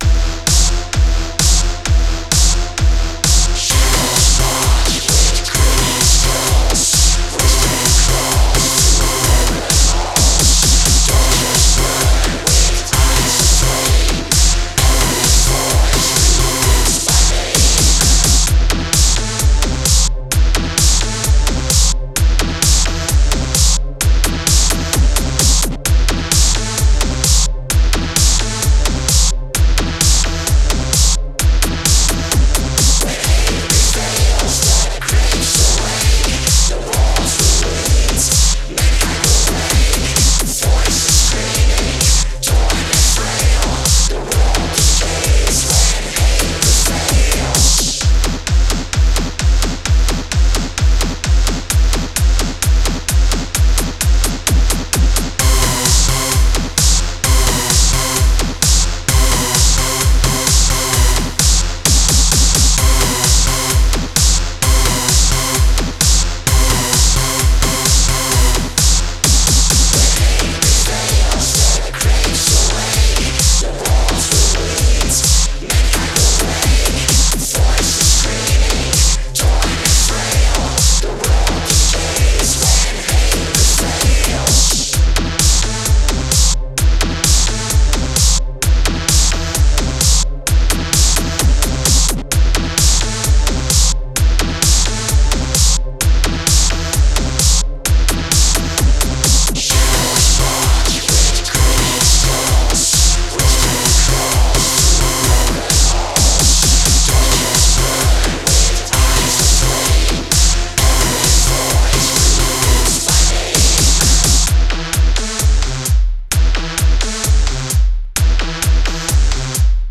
Dark Electro, Aggrotech